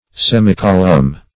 Search Result for " semicolumn" : The Collaborative International Dictionary of English v.0.48: Semicolumn \Sem"i*col`umn\, n. A half column; a column bisected longitudinally, or along its axis.